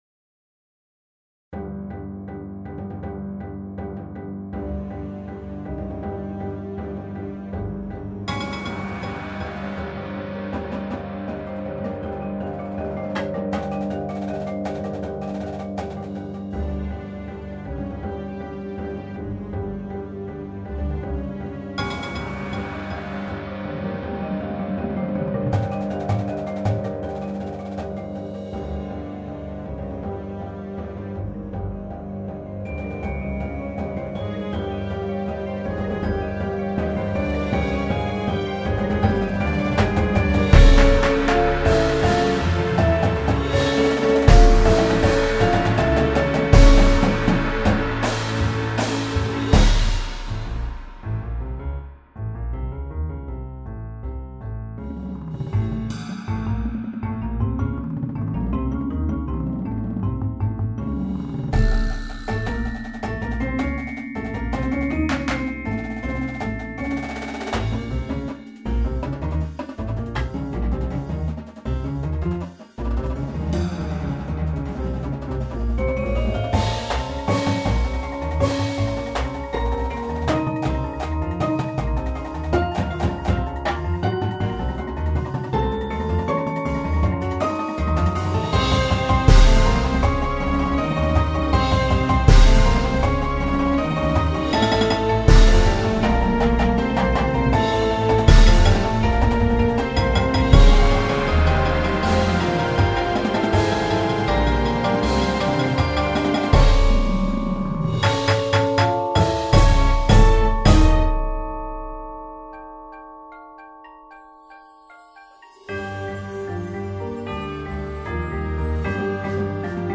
Snare Line
Tenor Line (Quints)
Bass Drum Line (5)
Cymbal Line
Marimba 1, 2
Xylophone 1, 2
Vibes
Glockenspiel
Bass
Synth 1, 2
Auxiliary Percussion 1, 2, 3